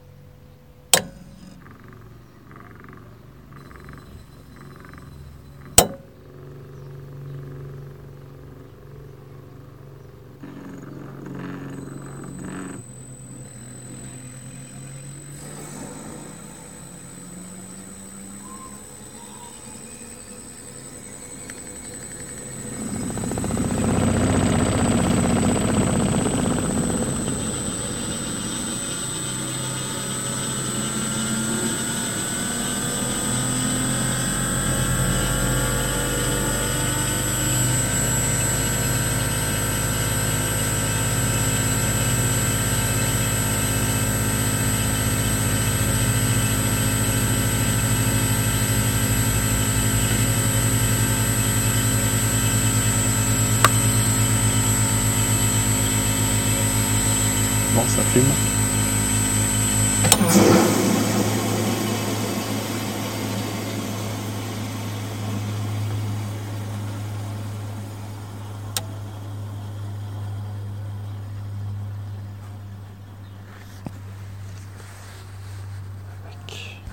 Le compresseur semble tourner.
On peut entendre le drôle de bruit au moment ou je coupe le courant.